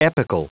Prononciation du mot epical en anglais (fichier audio)
Prononciation du mot : epical